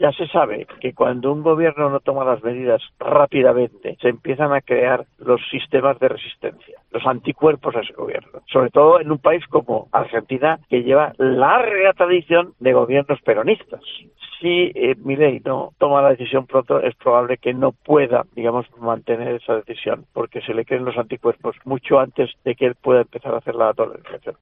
El economista